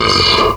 MONSTERS_CREATURES
MONSTER_Noise_07_mono.wav